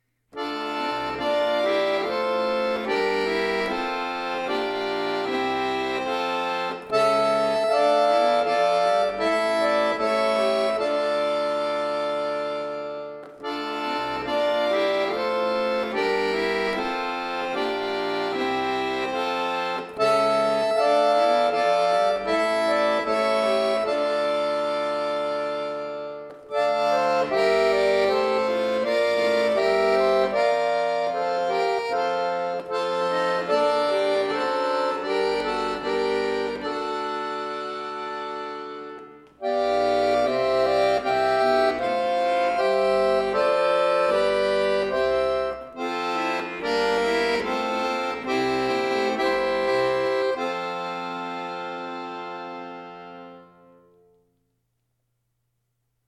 Klassisch